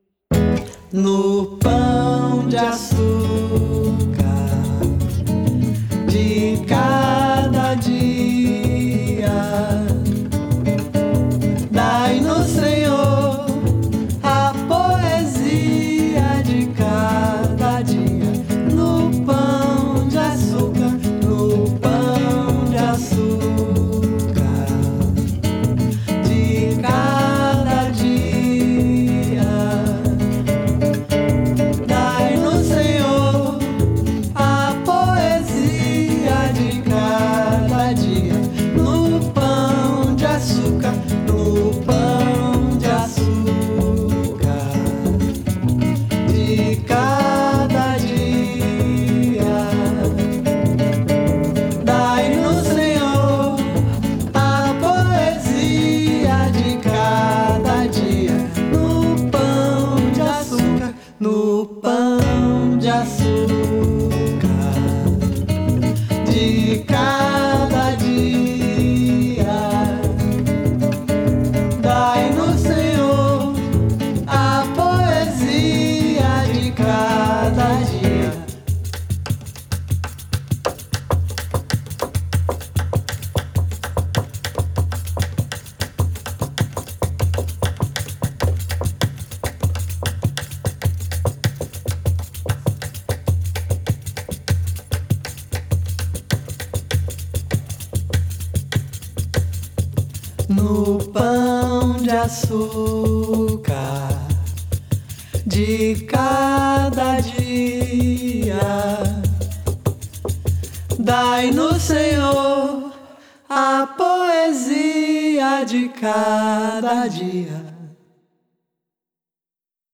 at Princeton University
Princeton University presents the concert
cello
percussion